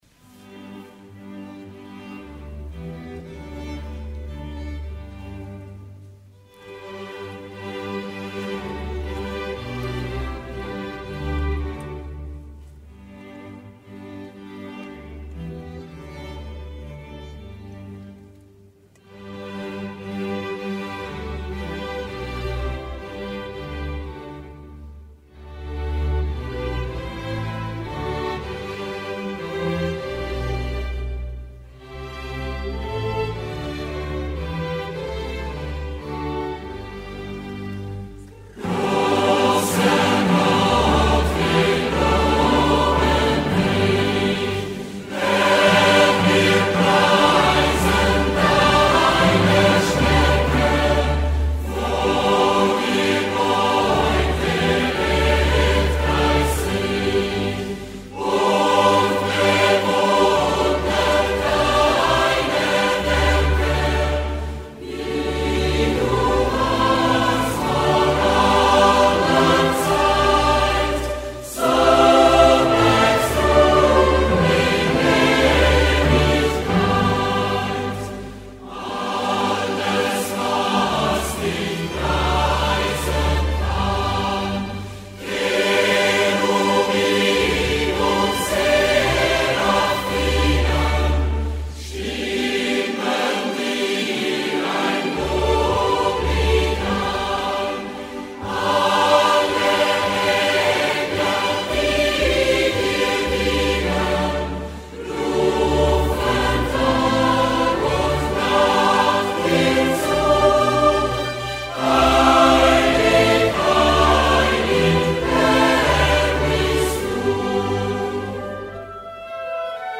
Musique.